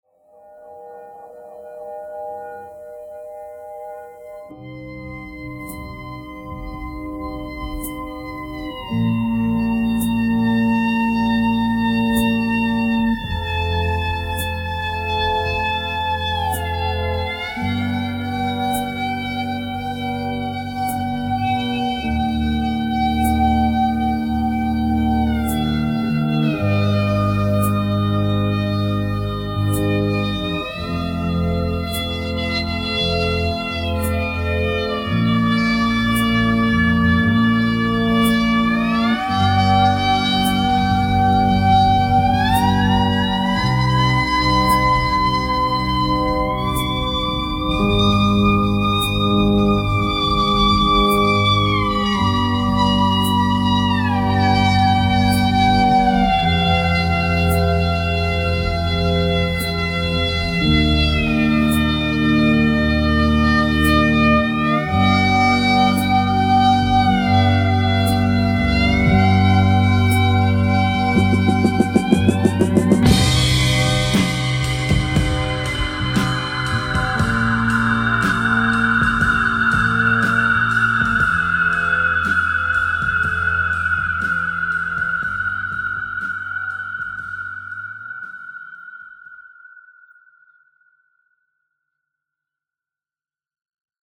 ~ 380 ms – long feedback
Black Strat w/ slide> Fuzz > ECHOLTD 2° > Amp